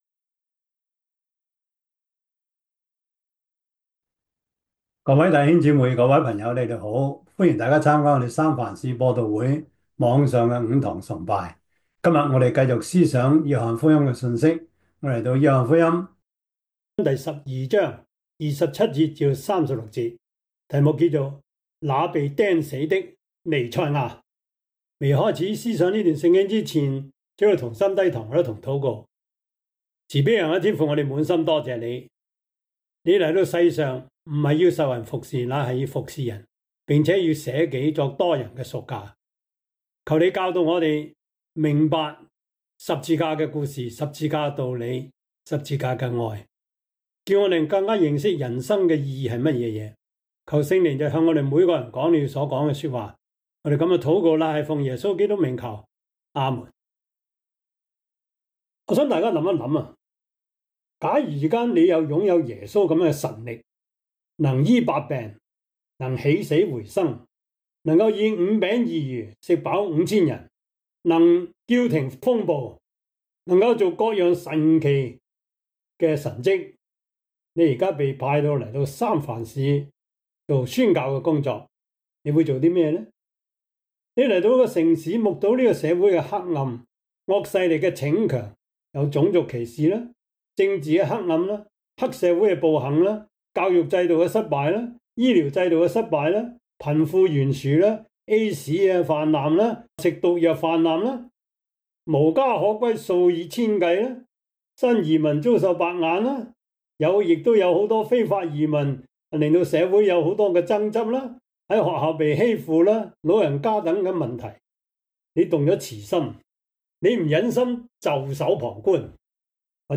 約翰福音 12:27-36 Service Type: 主日崇拜 約翰福音 12:27-36 Chinese Union Version